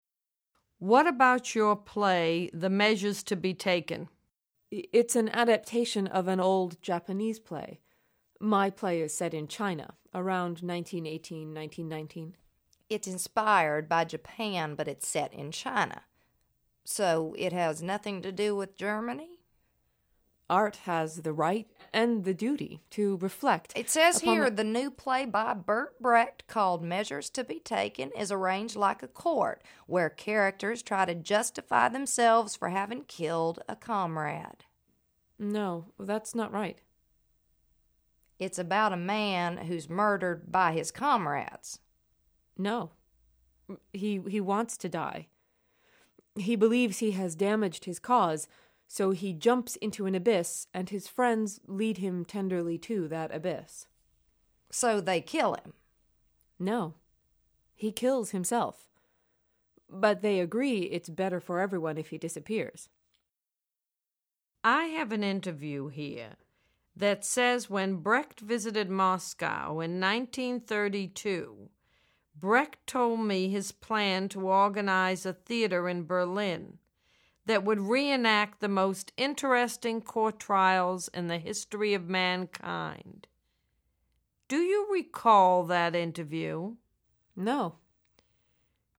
A synchronized audio track with the voices of three actors performing excerpts from actual House Committee transcripts accompanies the slide show.